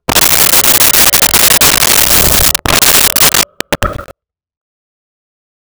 Creature Breath 03
Creature Breath 03.wav